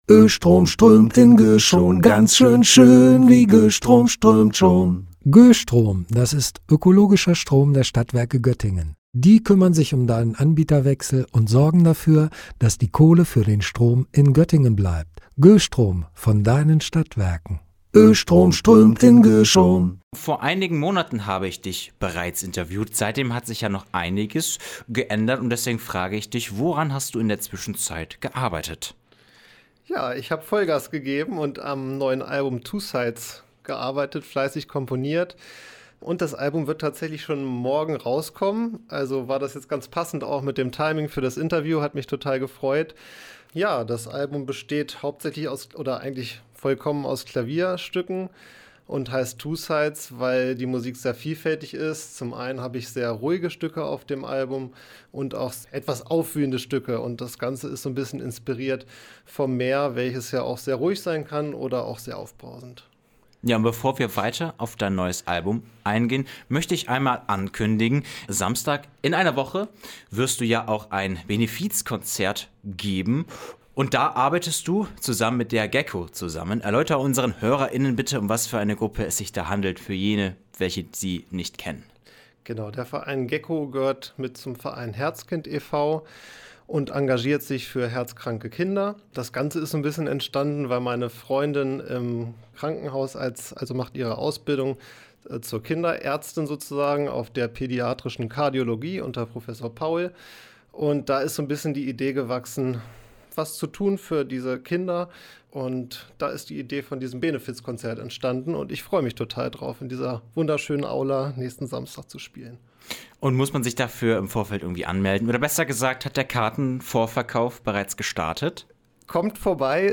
Pianist und Komponist